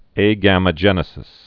(ā-gămə-jĕnĭ-sĭs, ăgə-mō-)